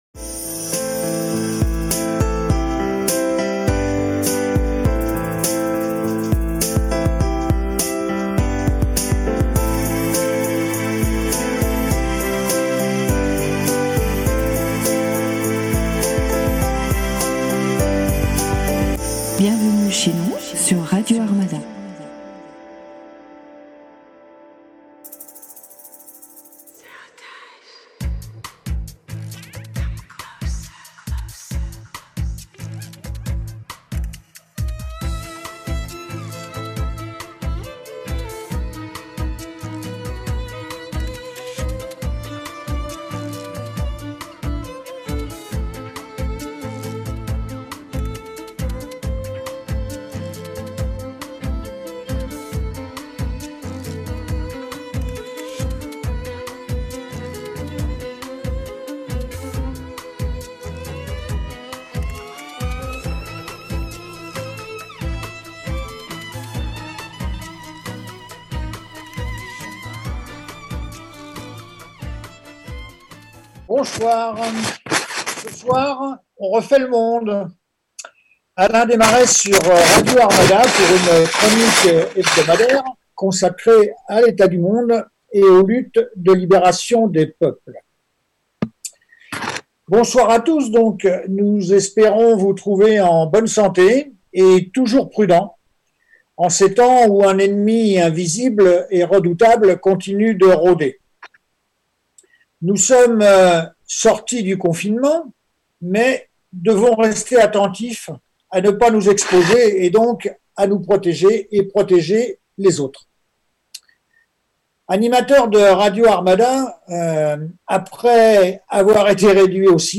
journaliste spécialiste des questions internationales